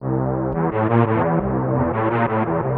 Convoy Strings 05.wav